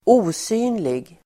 Uttal: [²'o:sy:nlig]